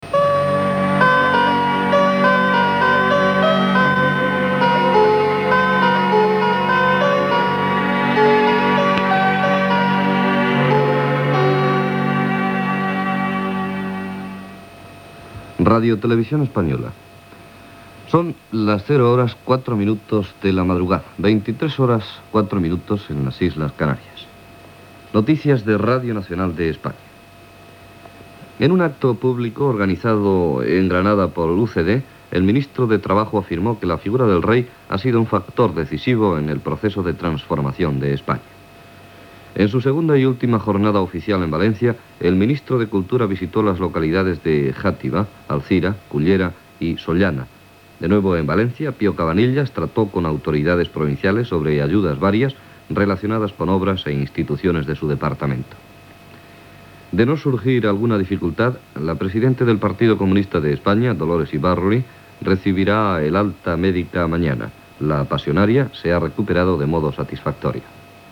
Sintonia, identificació com a "RTVE", hora.
Informatiu